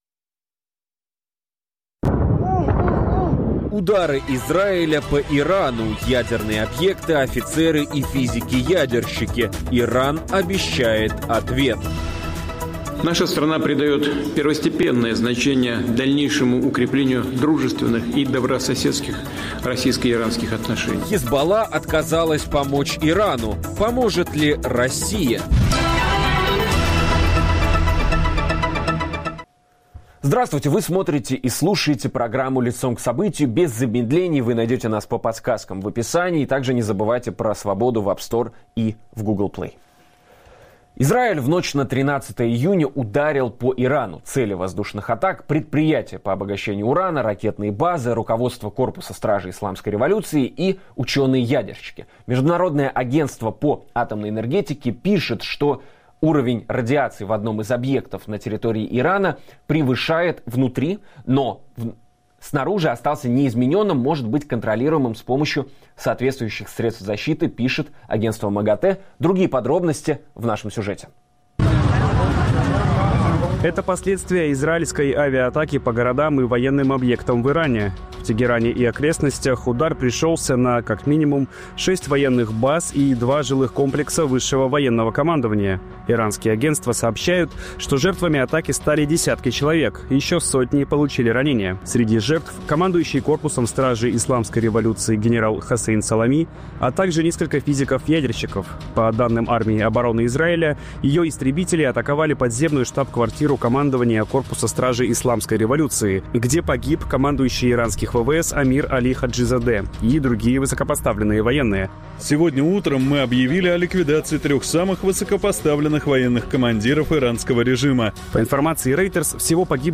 Чем обернется удар Израиля по Ирану для мировой политики, нефтяных цен и России, возможна ли полномоштабная война на Ближнем Востоке, а также о том какую роль Россия займет в конфликте Израиля и США? Об этом говорим с востоковедом